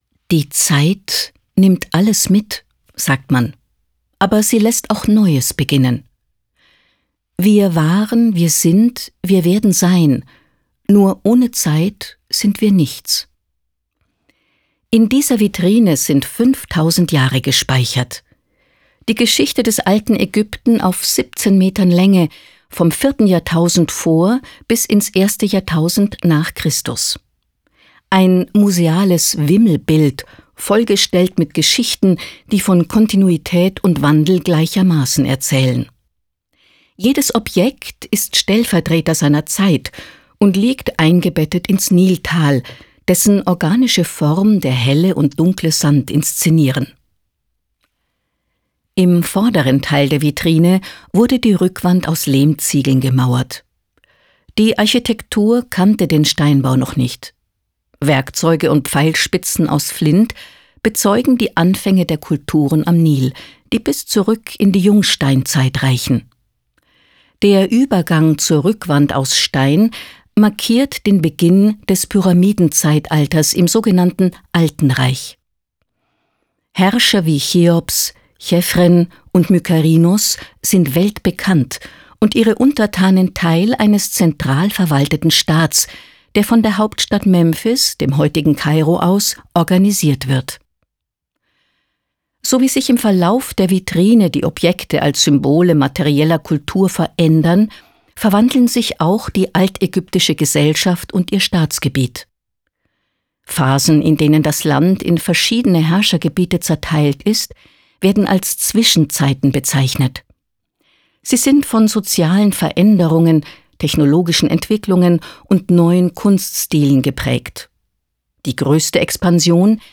Ägyptische Highlights auf die Ohren